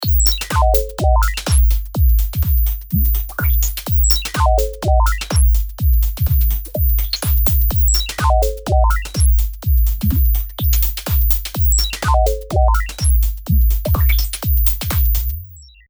次にパンを左右に振り分けてみます。
今回はドラムにフィルターをかけていますが、飛び道具系のサウンドとかにこういう効果はアリなんじゃないかと思います。